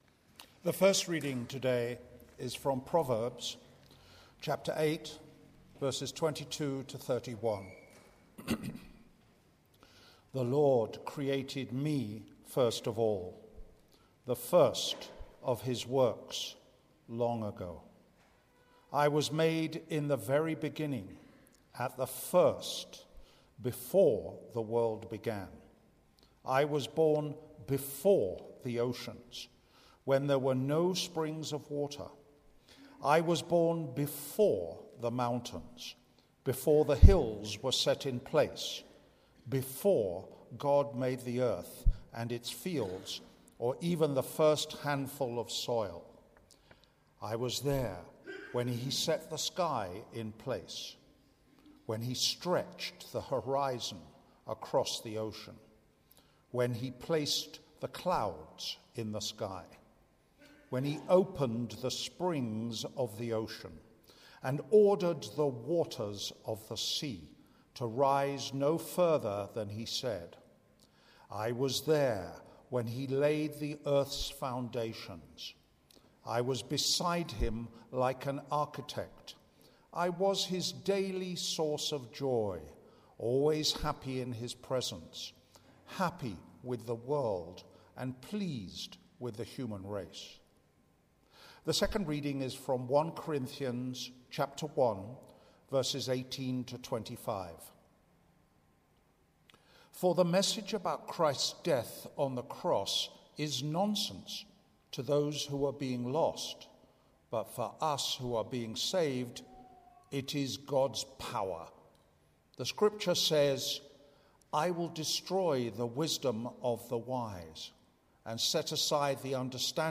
9:30am Fusion Service from Trinity Methodist Church, Linden, Johannesburg
Sermons